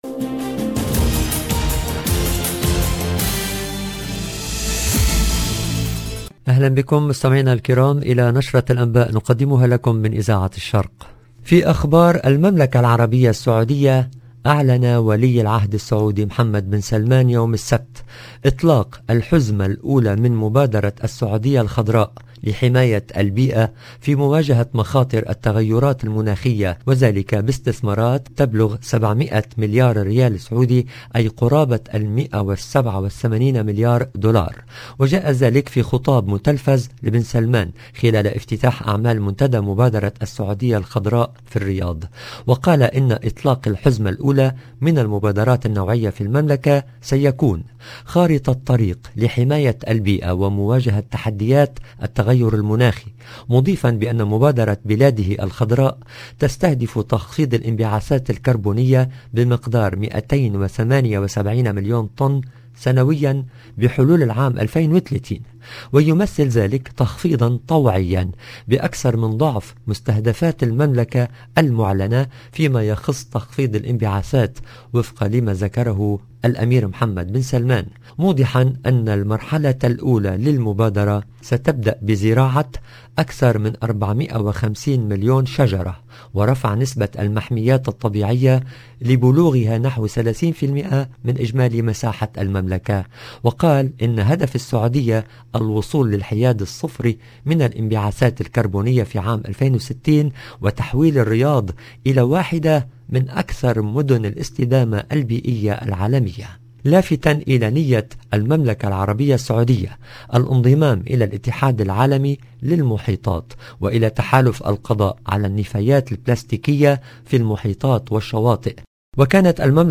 LE JOURNAL DU SOIR EN LANGUE ARABE DU 23/10/21